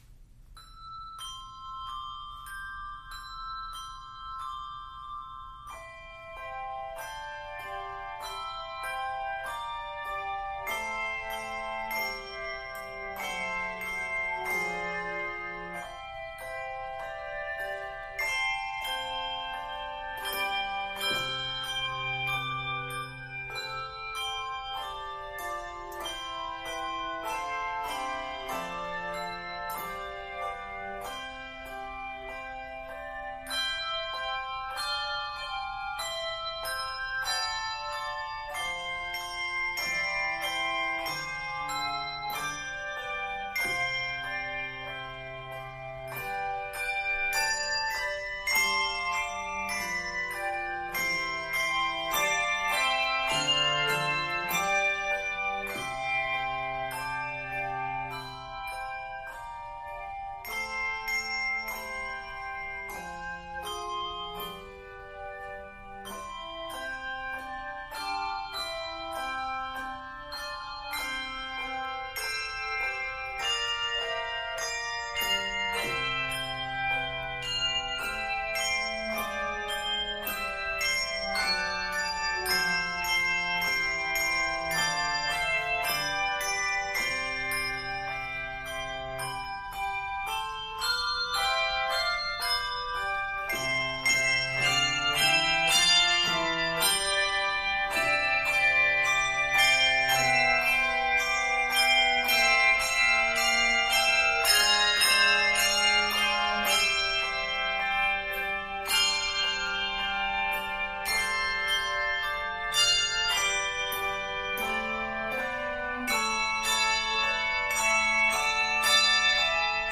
set for 3-5 octaves